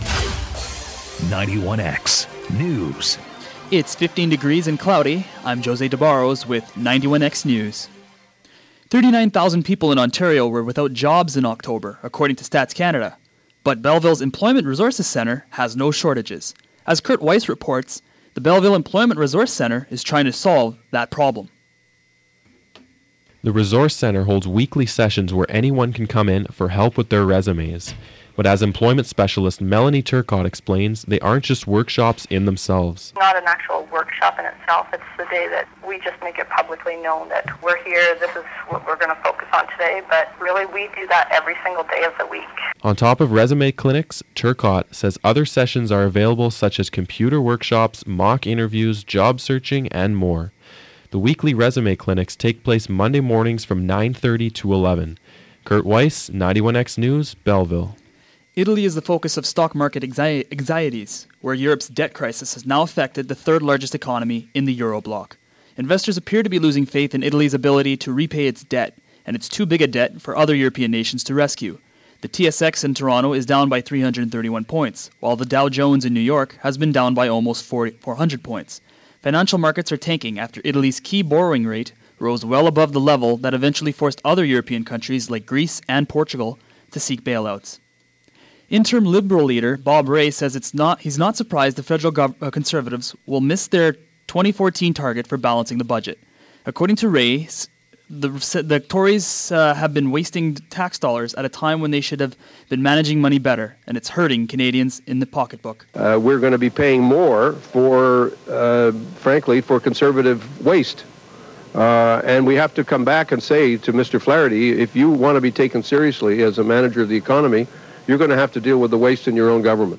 91x-news-november-9-4pm.mp3